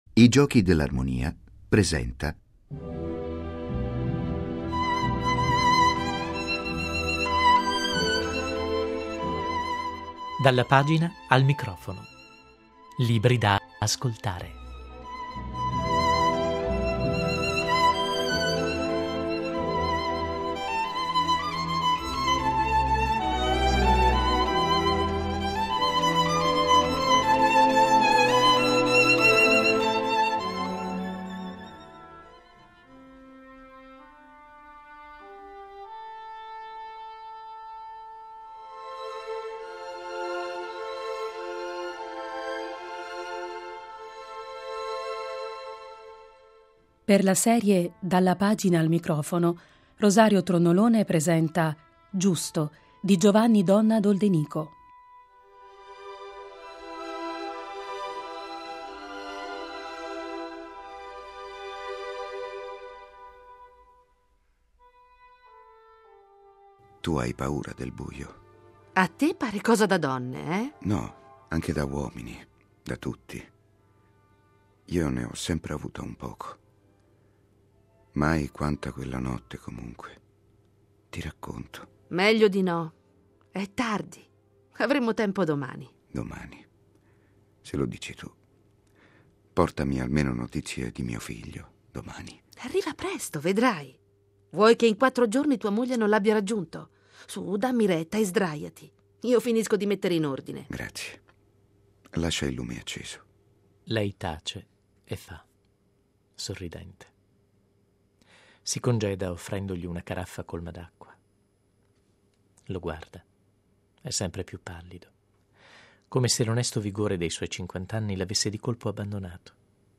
martedì 18 marzo ore 21.00 In occasione della Solennità di San Giuseppe, vi proponiamo la riduzione radiofonica del romanzo "Giusto" di Giovanni Donna d'Oldenico: una biografia romanzata degli ultimi giorni di vita del padre putativo di Gesù.